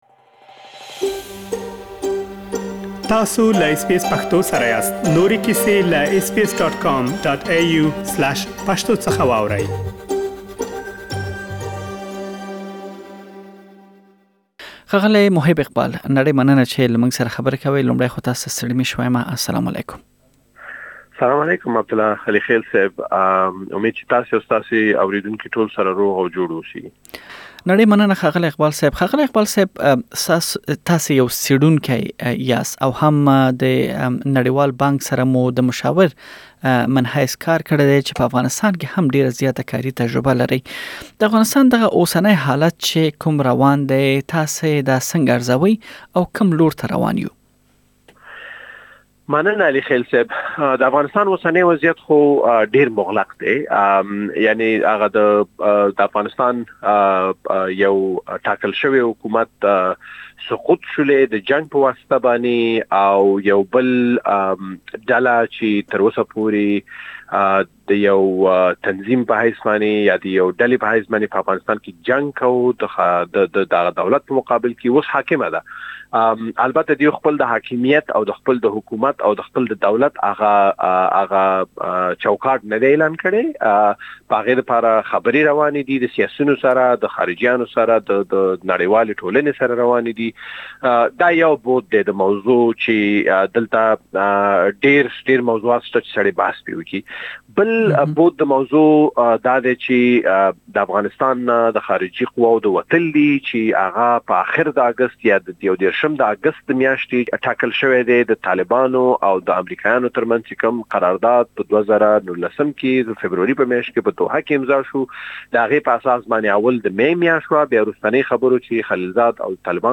بشپړې مرکه